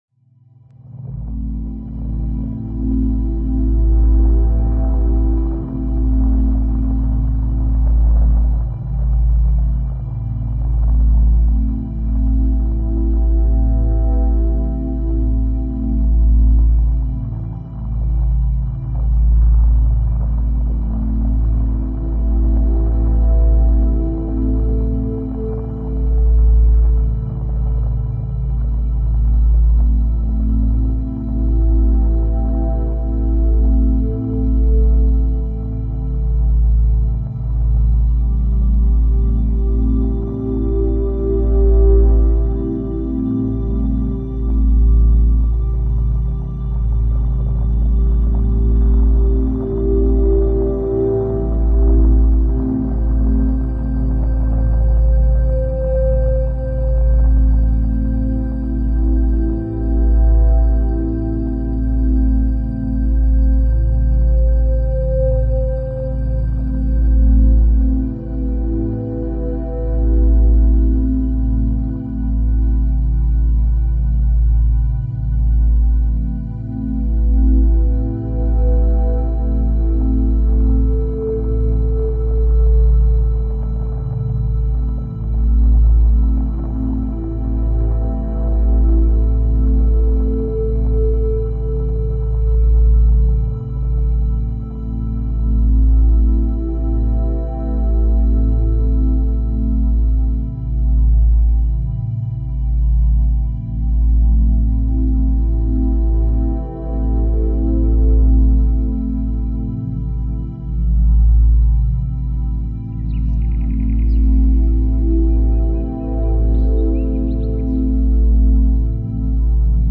nature sounds and atmospheric sounds, perfect for relaxation